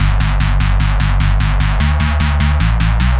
Index of /musicradar/future-rave-samples/150bpm